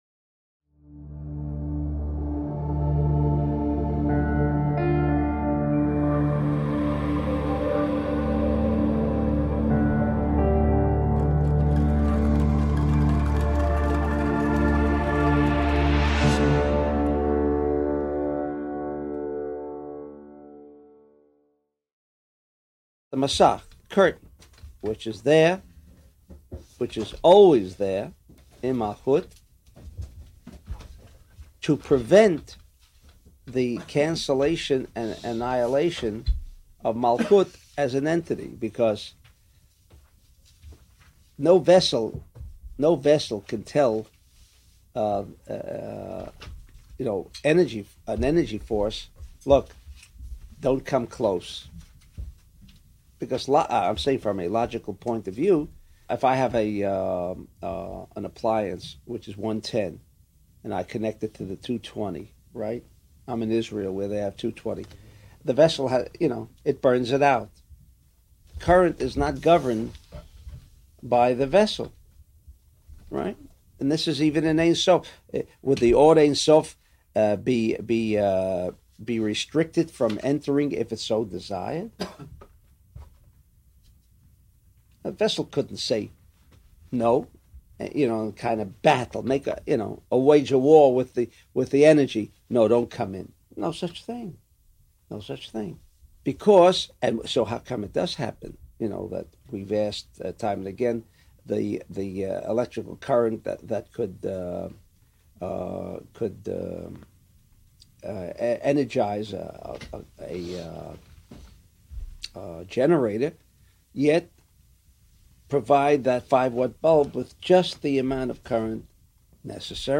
A small group of students gathered before dawn to delve into some of the deepest secrets of Kabbalah. For many years, these lessons were only available in VHS and audio cassette formats.